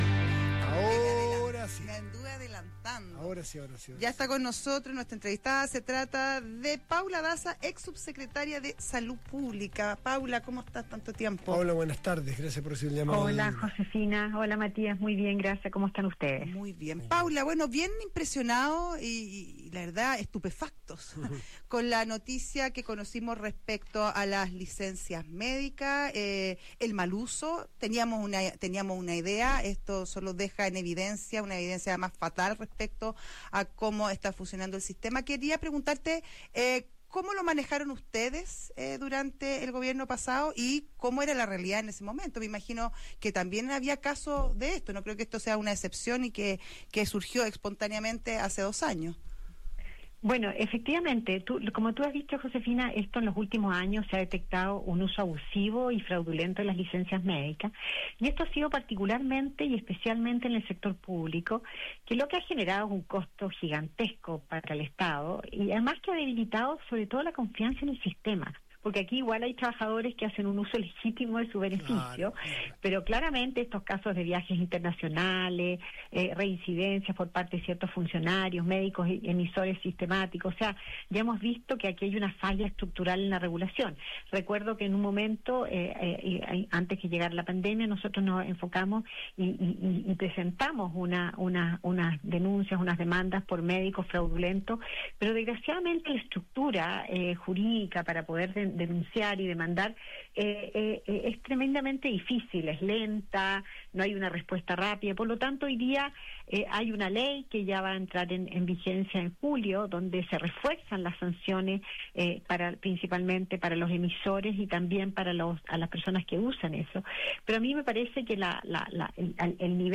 La directora ejecutiva de CIPS UDD, Paula Daza, conversó con Radio Duna sobre el mal uso de las licencias médicas y el informe de la Contraloría General de la República que da cuenta de que entre 2023 y 2024 más de 25.000 funcionarios públicos viajaron fuera del país durante su licencia médica.